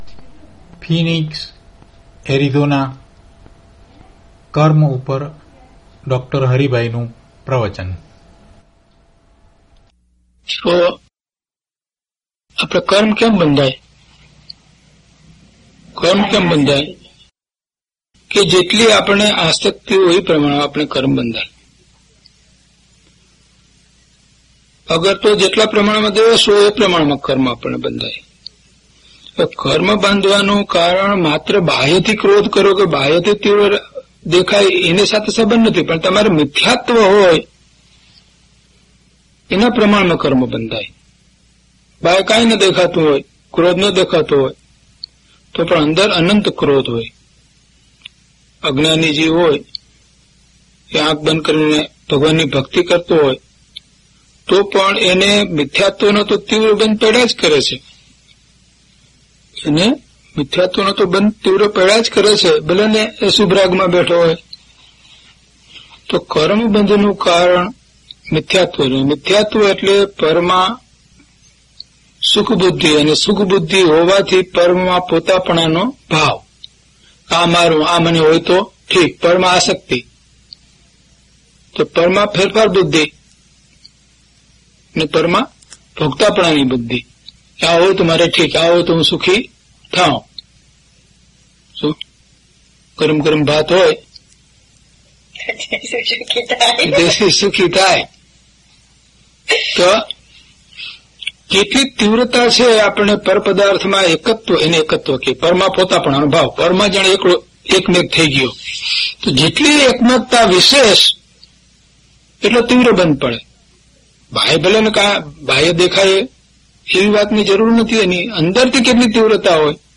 DHP039 KARMA - Pravachan.mp3